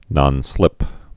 (nŏnslĭp)